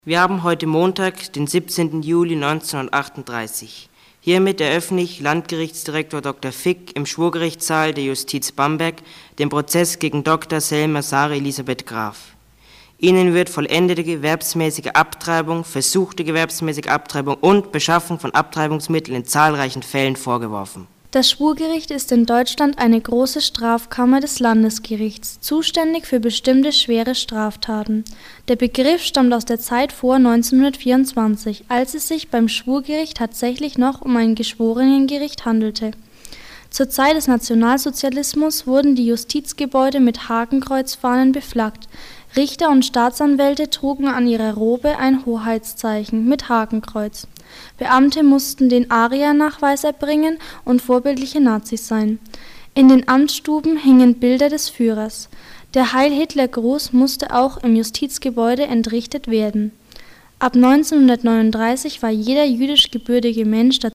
Hörstück und szenische Lesung zur Gerichtsverhandlung von